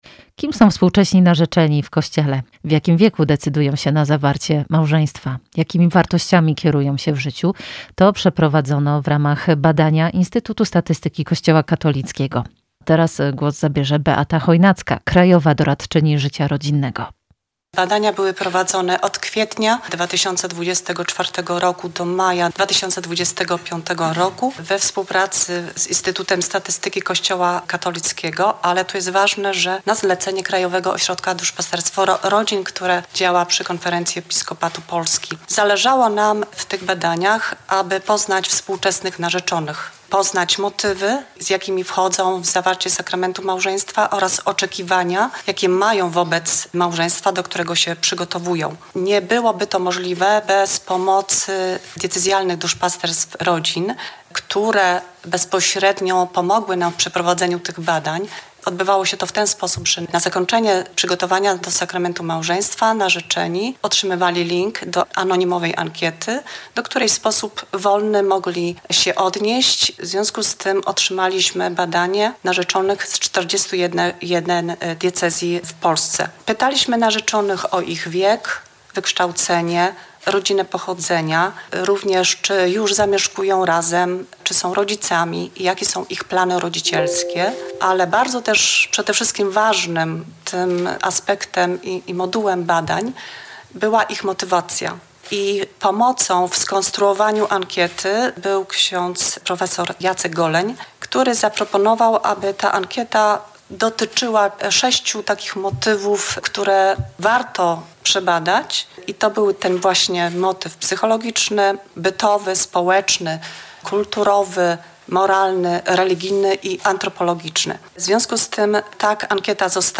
W konferencji wzięli udział: abp Wiesław Śmigiel, metropolita szczecińsko-kamieński, przewodniczący Rady Konferencji Episkopatu Polski ds. Rodziny